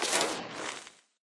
creeperdeath.ogg